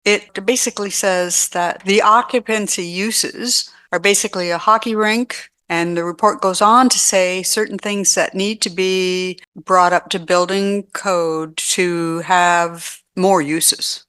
A hefty price tag of nearly half a million dollars is what’s needed to bring the Stephen Arena up to code compliance – that’s according to an architectural report presented to South Huron Council on Monday (June 17th).
The current classification of the arena allows for limited uses – as indicated by Councillor Marissa Vaughan.